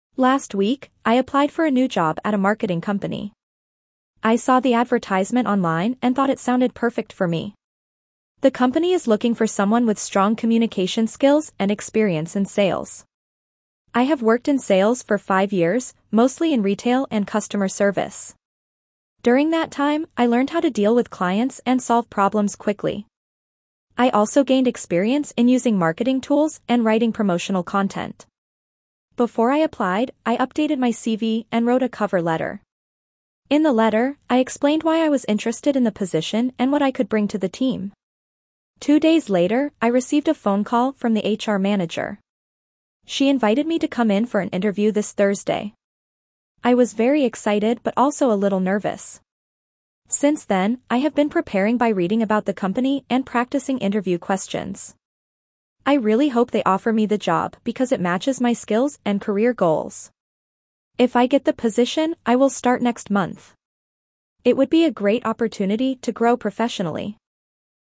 Dictation B1 - New Job
1.-B1-Dictation-New-Job.mp3